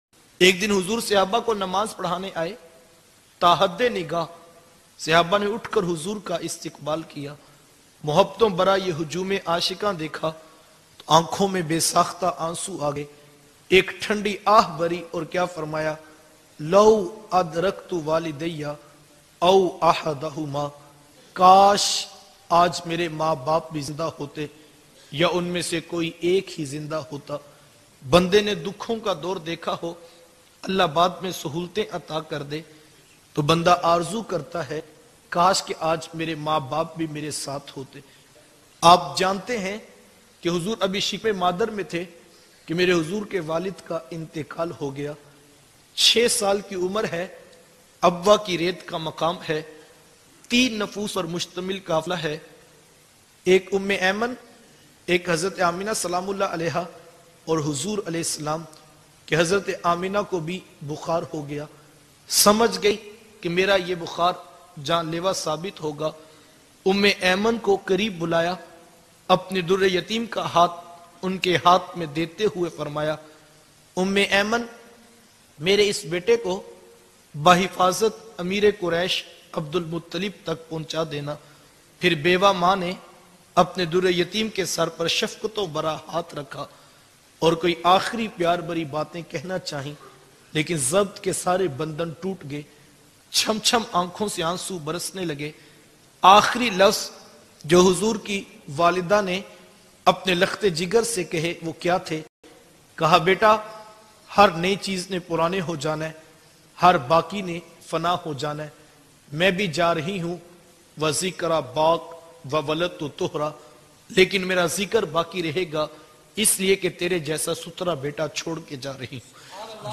Emotional bayan seerat e mustafa